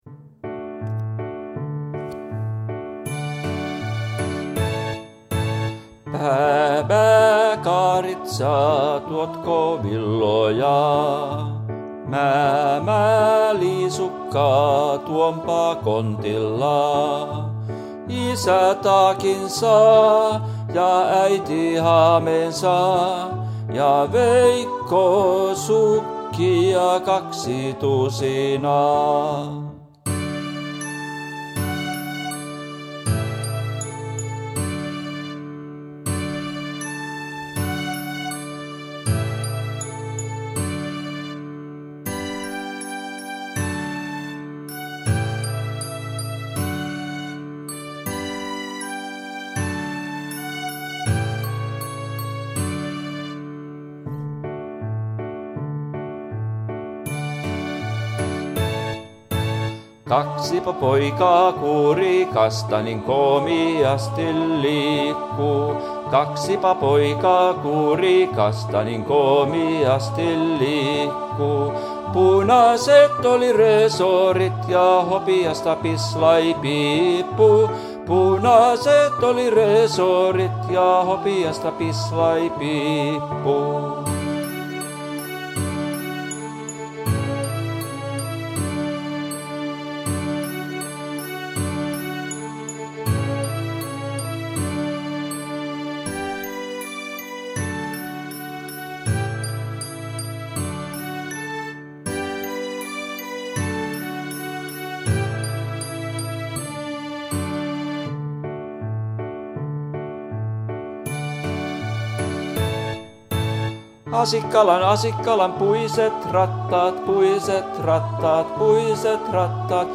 Bää bää potpurri laululla.mp3